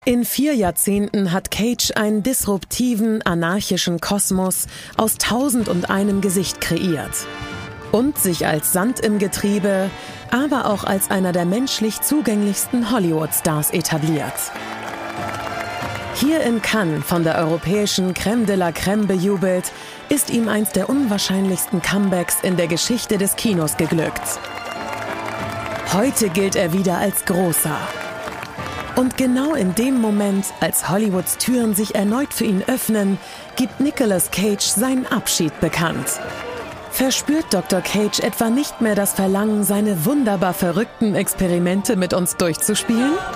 markant, sehr variabel
Mittel minus (25-45)
Norddeutsch
Commercial (Werbung)